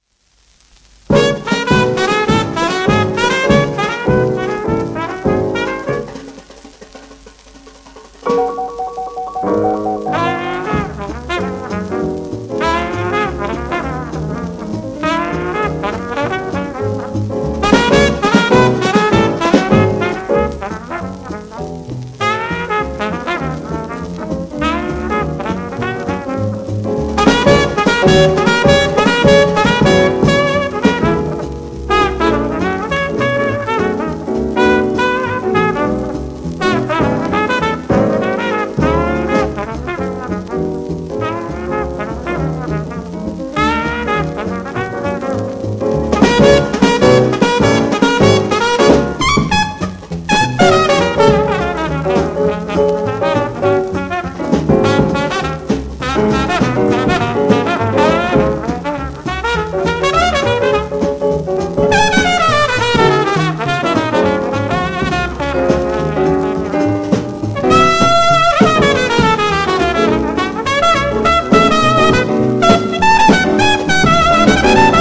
イギリスのトラッド、モダン・ジャズ界に君臨するトランペット奏者として知られた人物